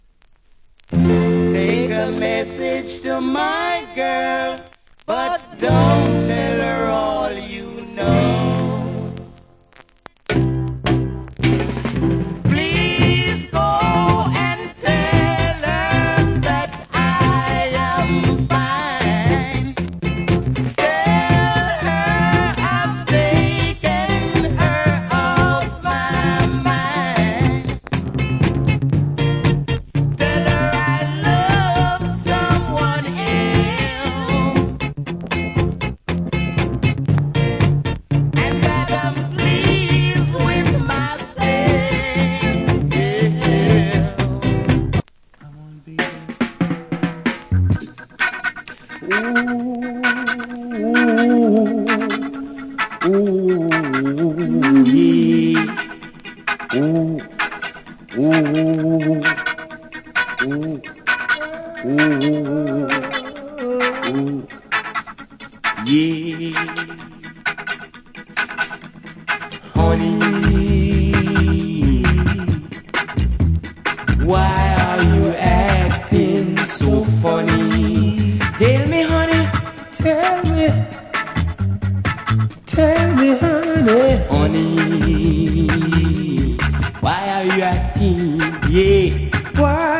Wicked Early Reggae Vocal.